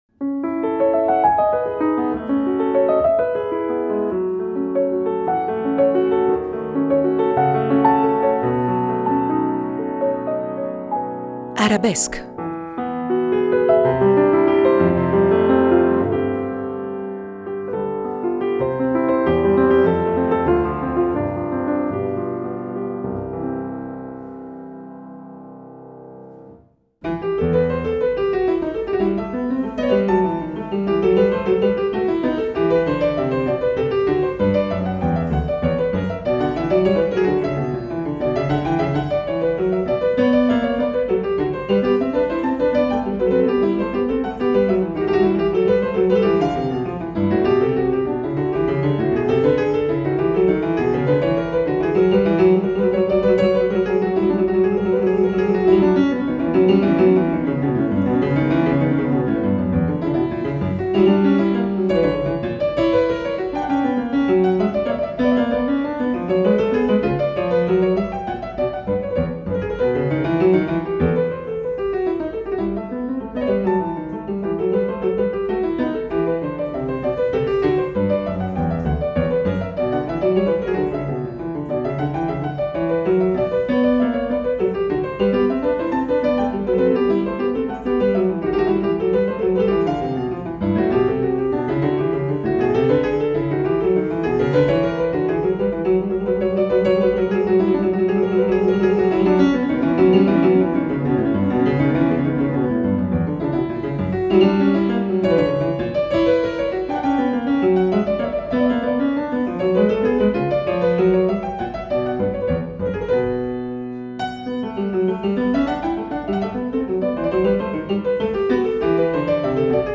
pianista